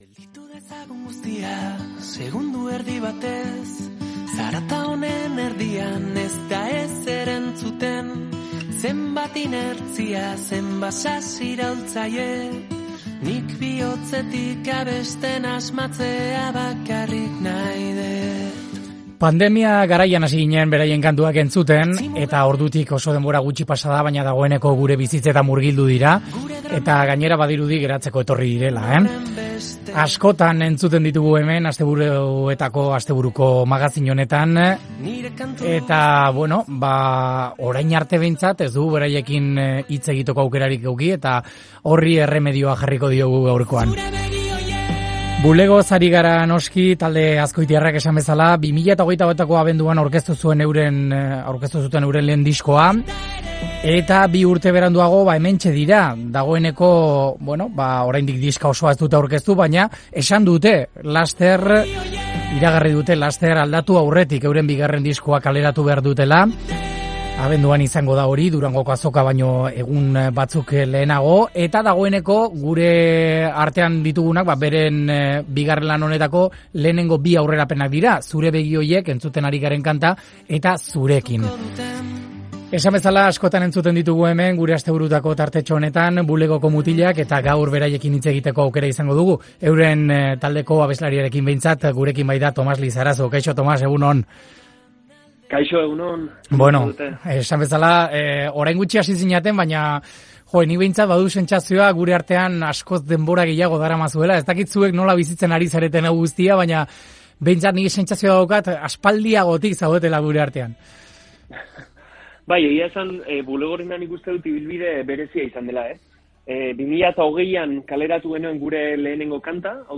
Lan berriaz, bizitakoaz, etorkizunaz, euskal musikaz, kultura poperoaz... aritu zaigu gaur Onda Vascan